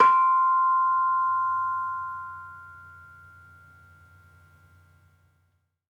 Bonang-C5-f.wav